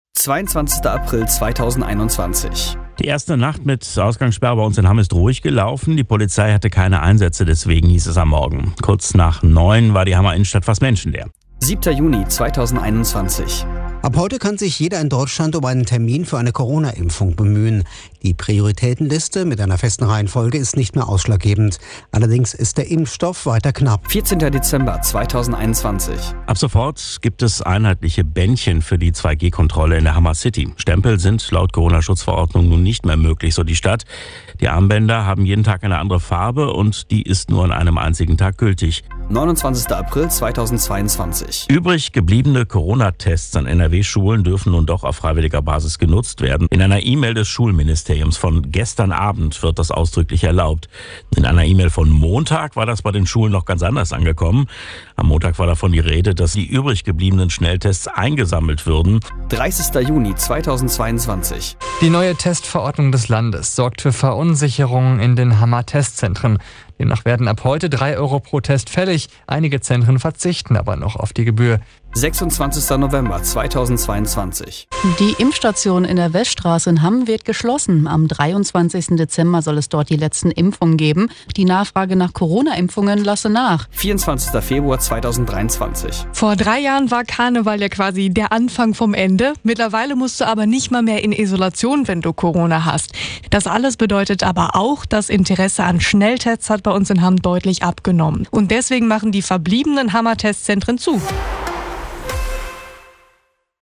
Diese Tonschleife wird von der Radio Lippewelle Hamm für das HammWiki zur Verfügung gestellt und unterliegt dem Urheberrecht.
News-Zusammenschnitt-Corona-Lockerungen.mp3